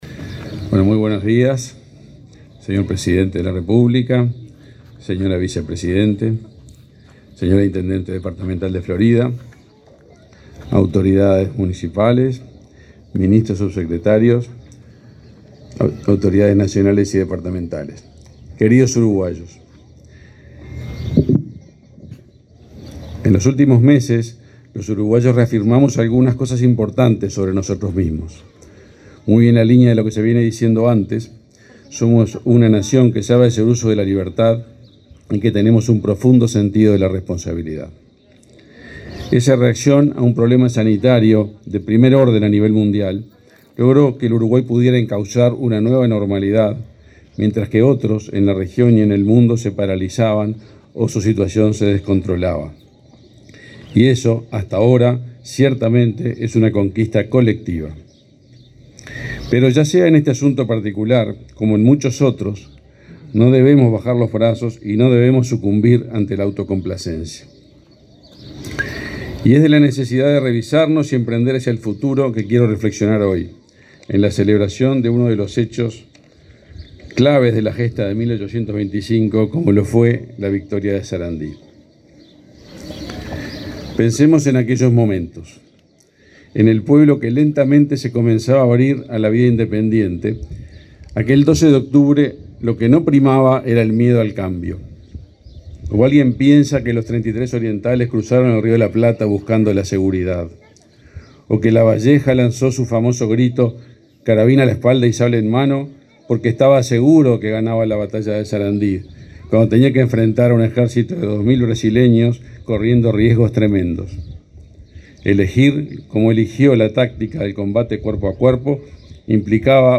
En el contexto de la Cruzada Libertadora, la batalla de Sarandí implicó el enfrentamiento entre tropas del Imperio del Brasil y las milicias independentistas orientales, que salieron victoriosas el 12 de octubre de 1825. En el acto, realizado en la plaza Gallinal de Sarandí Grande, en Florida, participaron el presidente Luis Lacalle Pou, la vicepresidenta Beatriz Argimón y el ministro de Industria, Omar Paganini.